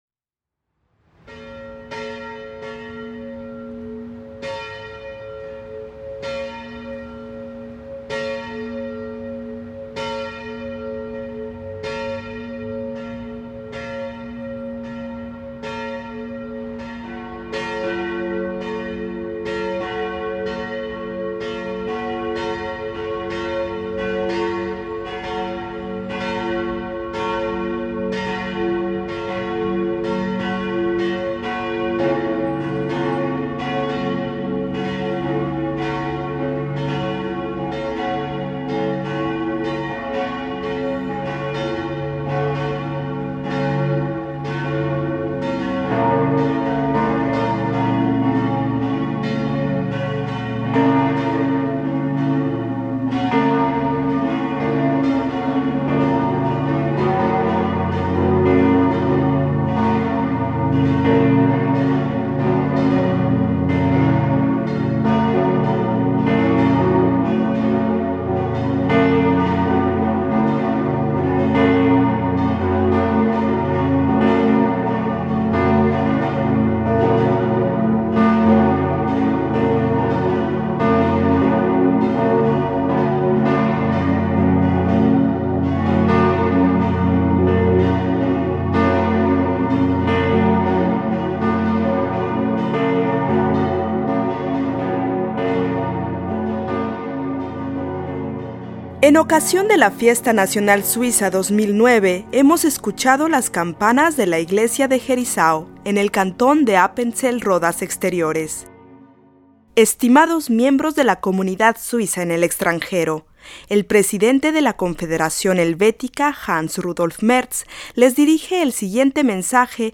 Discurso del presidente de la Confederación Hans-Rudolf Merz a los suizos del exterior.